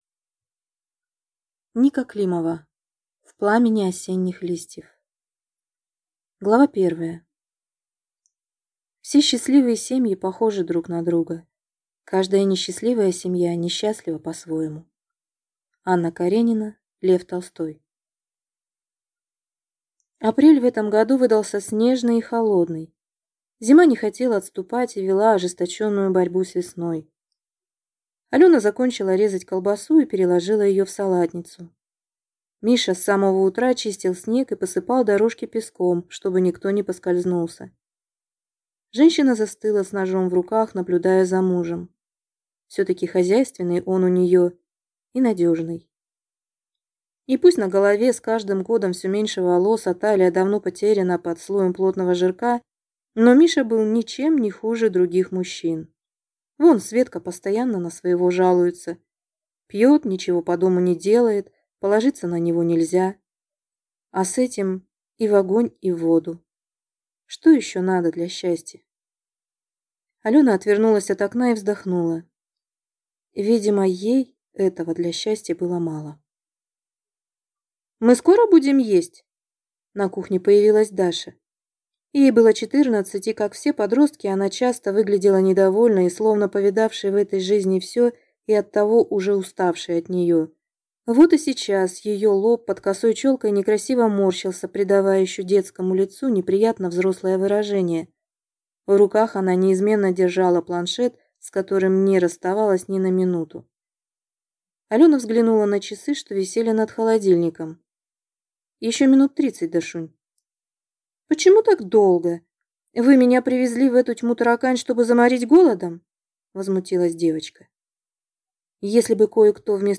Аудиокнига В пламени осенних листьев | Библиотека аудиокниг